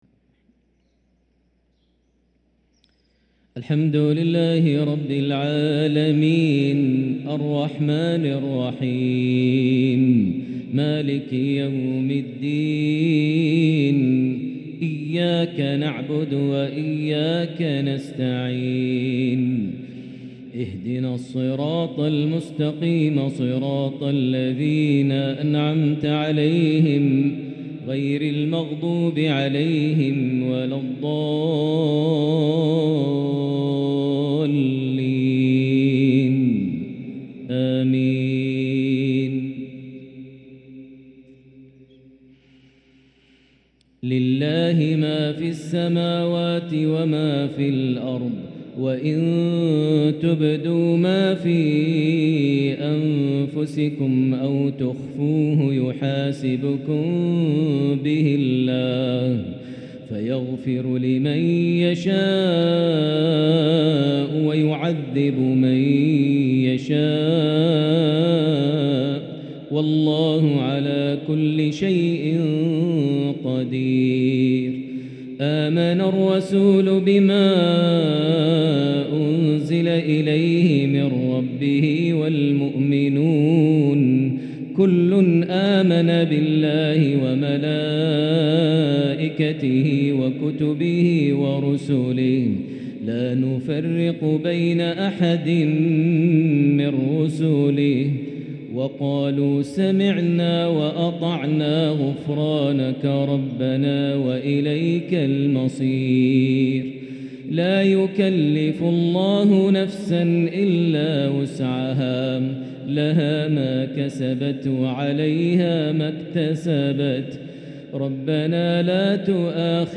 تلاوة لخواتيم سورتي البقرة (284- 286) و التوبة (126-129) مغرب الجمعة 4-8-1444هـ > 1444 هـ > الفروض - تلاوات ماهر المعيقلي